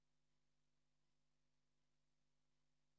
hopdrms3.wav